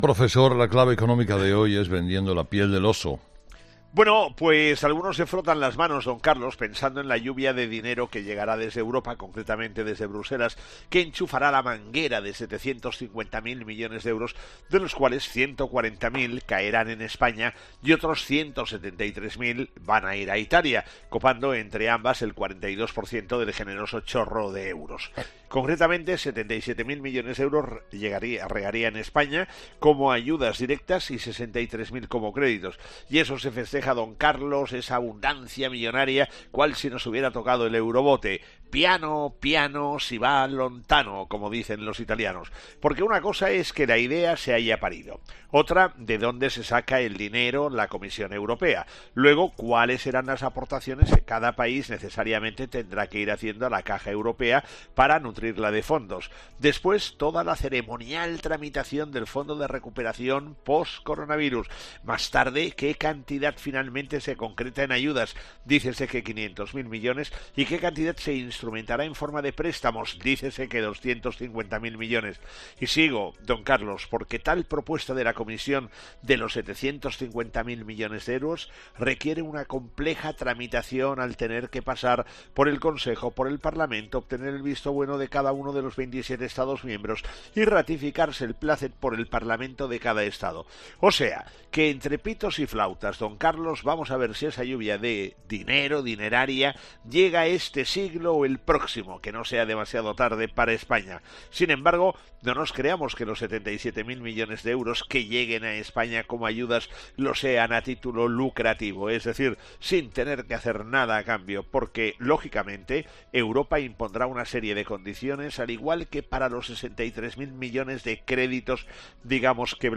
El profesor José María Gay de Liébana analiza en ‘Herrera en COPE’ las claves económicas del día